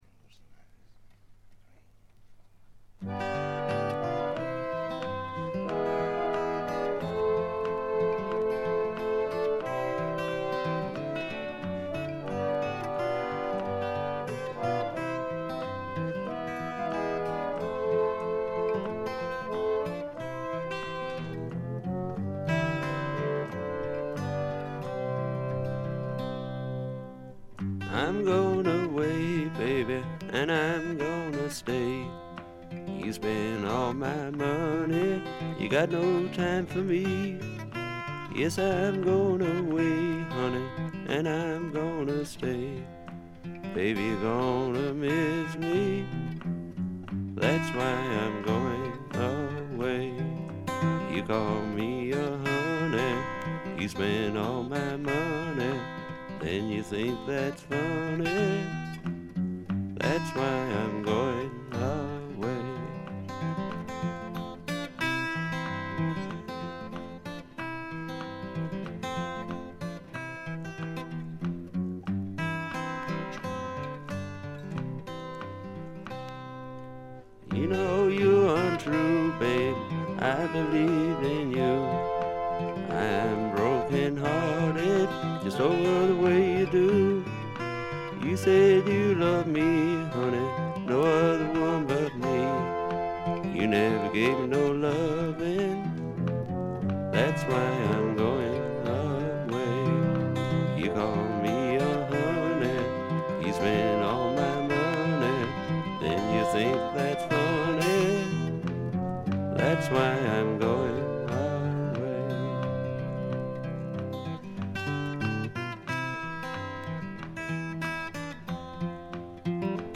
ほとんどノイズ感無し。
多少枯れぎみの哀愁を帯びた声で、ちょっと投げやりな歌い方もサウンドの雰囲気にマッチしています。
各面のラスト曲が味わい深いアコースティックなトラッドという構成もグッド。
試聴曲は現品からの取り込み音源です。